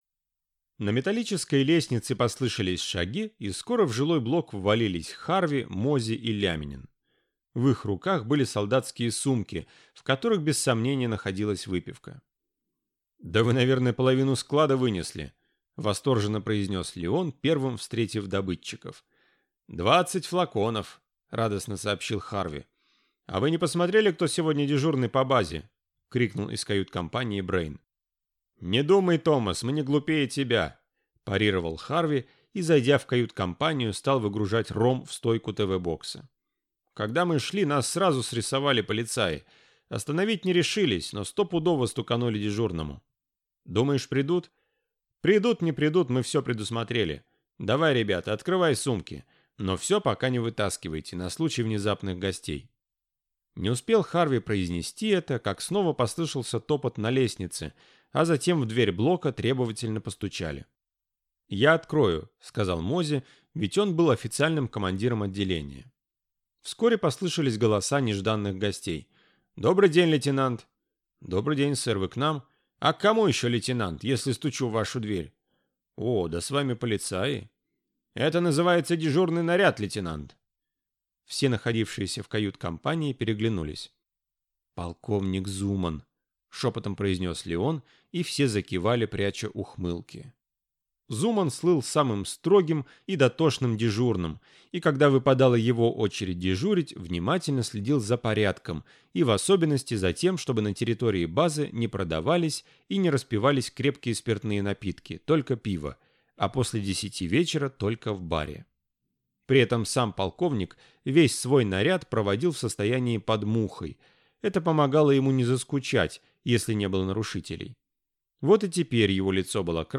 Аудиокнига Игра без правил | Библиотека аудиокниг
Прослушать и бесплатно скачать фрагмент аудиокниги